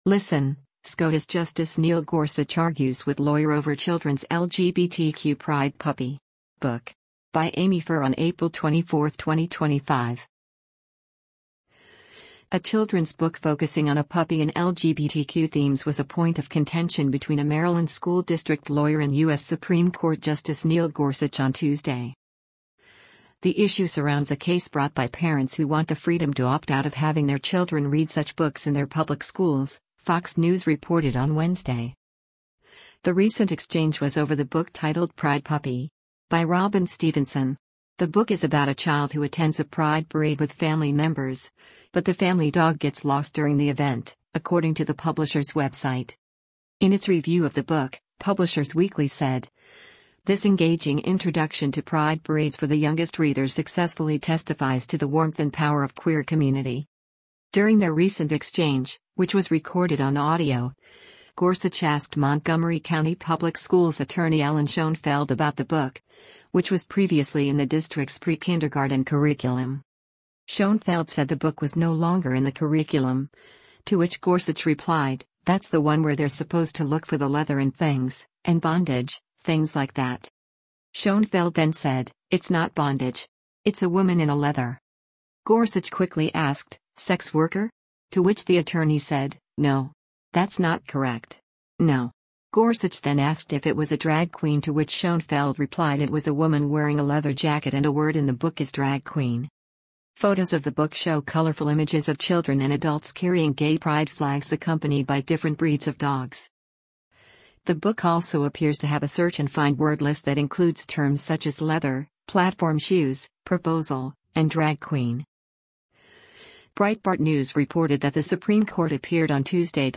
LISTEN: SCOTUS Justice Neil Gorsuch Argues with Lawyer over Children’s LGBTQ ‘Pride Puppy!’ Book